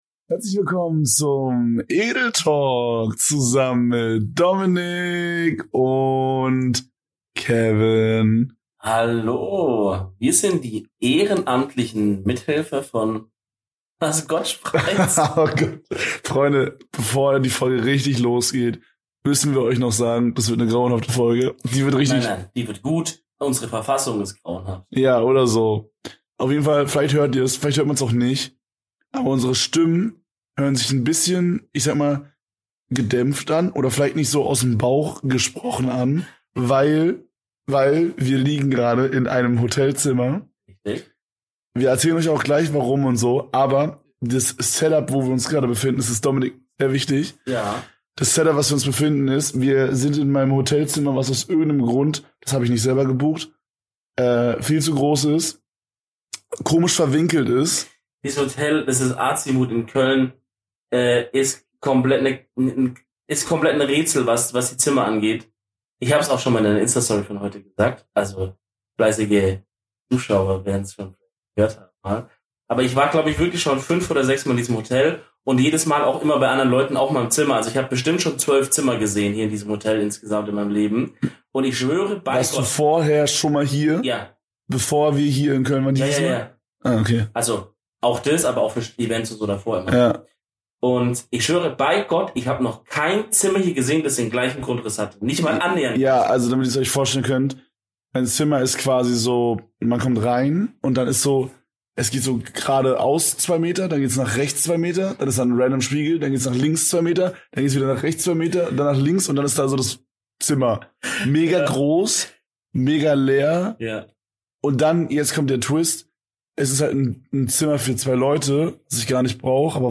Um 4 Uhr nachts einen Podcast aufnehmen?
Völlig übermüdet aufnehmen?
In einem Labyrinth Hotel Zimmer aufnehmen?